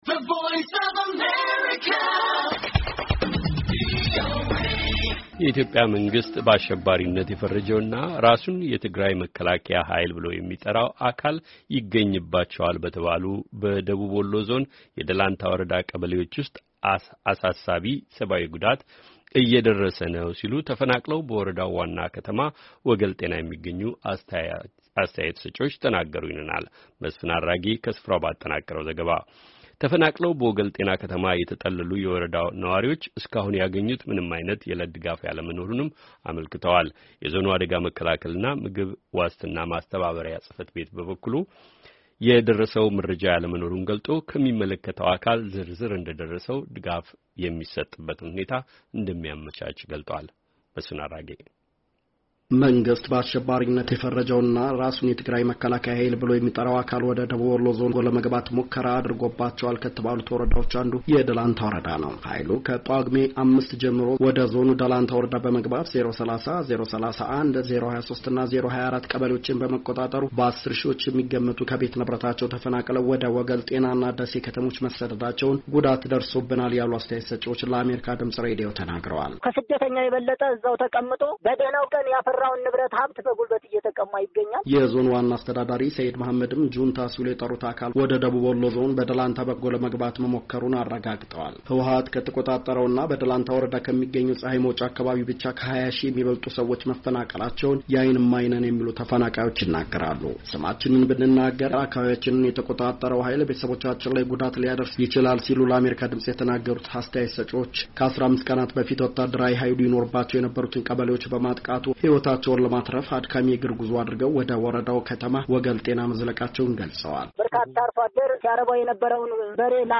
ዜና
የኢትዮጵያ መንግሥት በአሸባሪነት የፈረጀውና ራሱን የትግራይ መከላከያ ኃይል ብሎ የሚጠራው አካል ይገኝባቸዋል በተባሉት በደቡብ ወሎ ዞን የደላንታ ወረዳ ቀበሌዎች ውስጥ አሳሳቢ ሰብአዊ ጉዳት እየደረሰ መሆኑን ተፈናቅለው በወረዳው ዋና ከተማ ወገልጤና የሚገኙ አስተያየት ሰጭዎች ተናገሩ፡፡